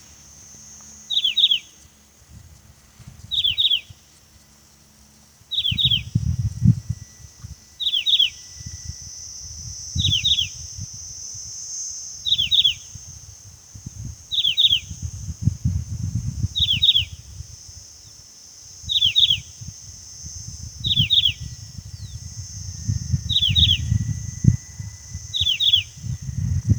Bico-de-pimenta-chaquenho (Saltatricula multicolor)
Nome em Inglês: Many-colored Chaco Finch
Fase da vida: Adulto
Localidade ou área protegida: Parque Nacional Sierra de las Quijadas
Condição: Selvagem
Certeza: Gravado Vocal